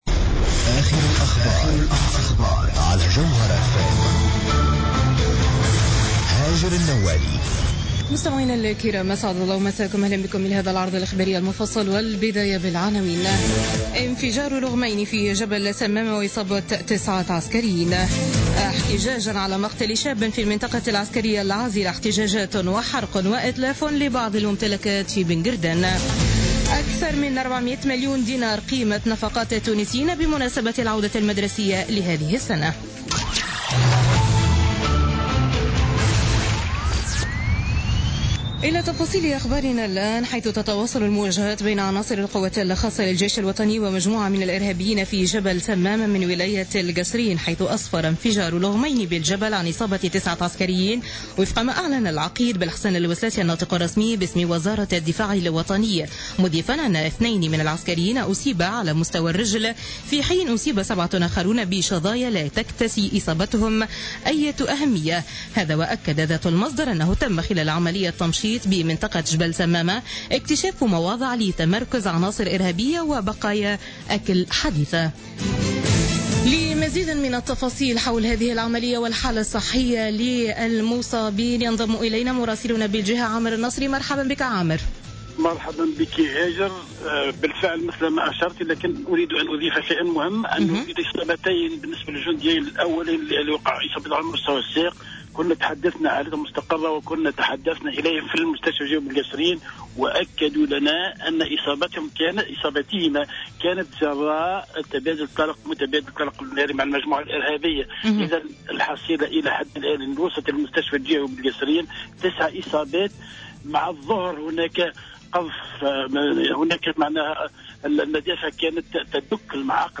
نشرة أخبار السابعة مساء ليوم الاثنين 5 سبتمبر 2016